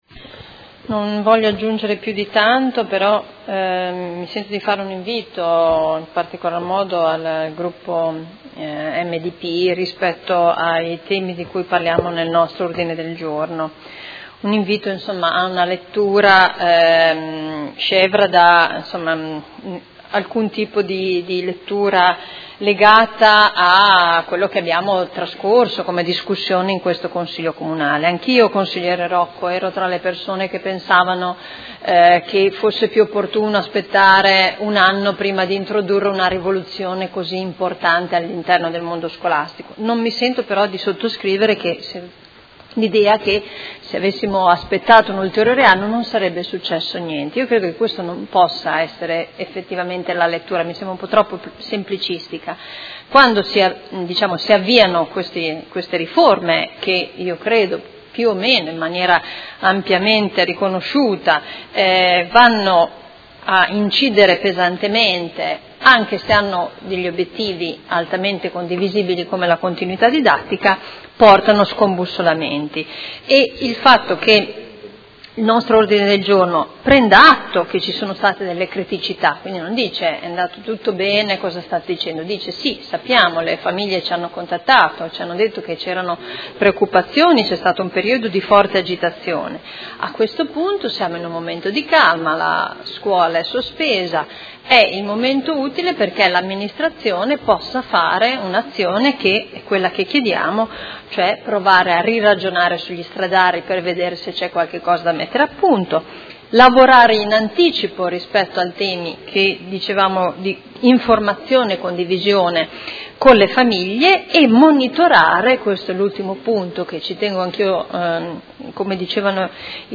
Simona Arletti — Sito Audio Consiglio Comunale
Seduta del 26/06/2017. Dibattito su Ordine del Giorno presentato dal Gruppo Forza Italia avente per oggetto: Istituti comprensivi e iscrizioni alle scuole medie: occorre un riesame per consentire il superamento delle difficoltà riscontrate dopo l’introduzione della riforma e Ordine del Giorno presentato dai consiglieri Baracchi, Lentini, Arletti, De Lillo, Venturelli, Di Padova, Forghieri e Pacchioni (P.D.) avente per oggetto: Regolamento iscrizioni Istituti comprensivi